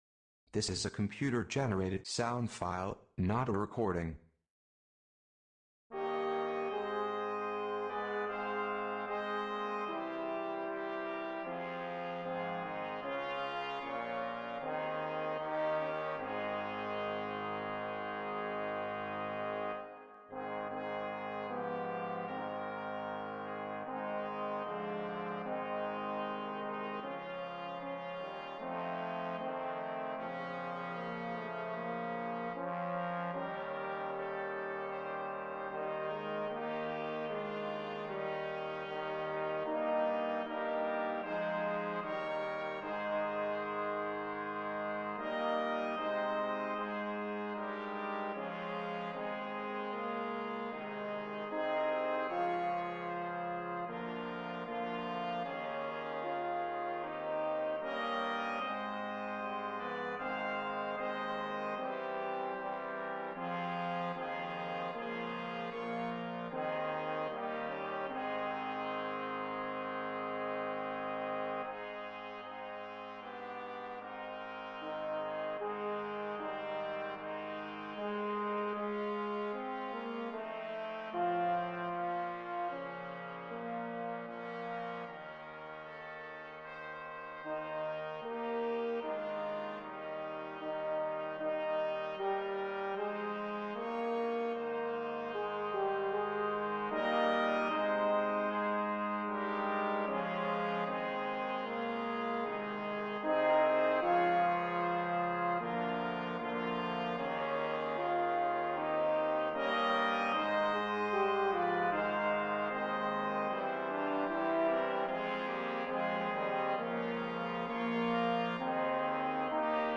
For Brass Ensemble
Arranged by . 2 Tpts., 2 Hns., 2 Tbns., Tba..